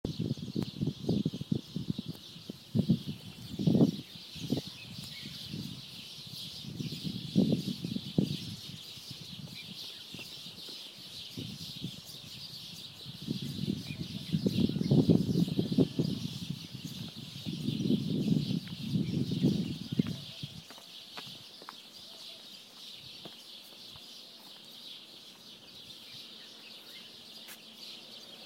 Background noise, 5:30am, Sea of Galilee
Birds. Wind. Flags. But mostly silence and stillness.